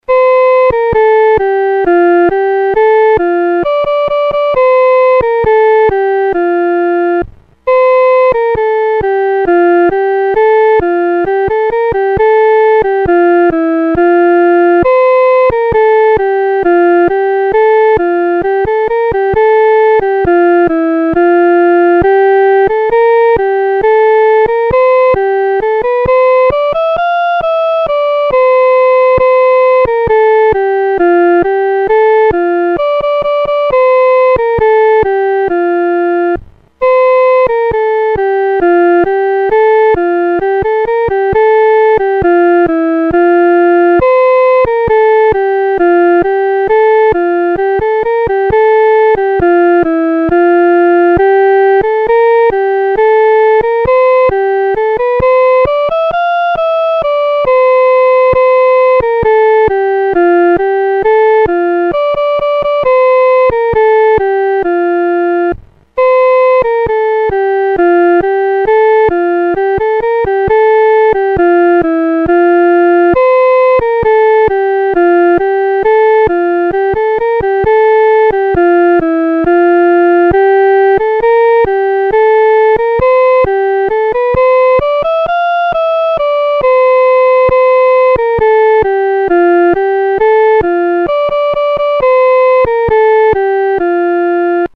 独奏（第一声）